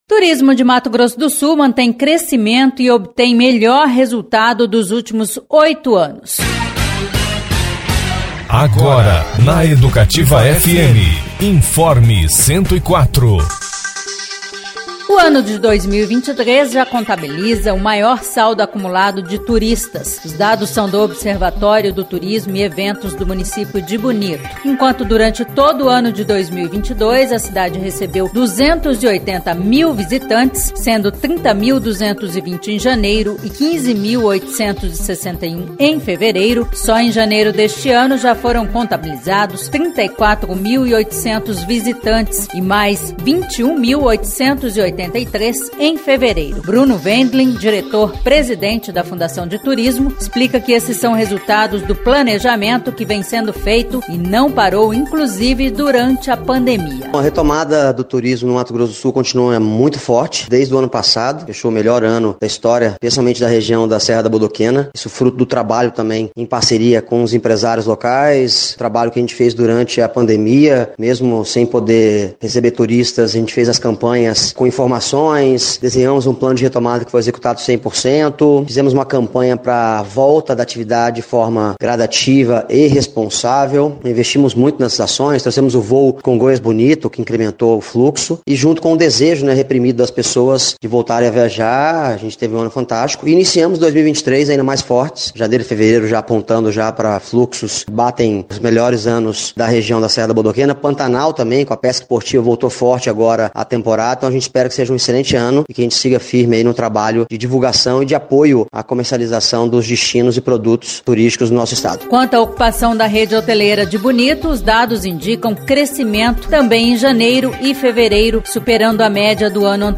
Saiba detalhes na reportagem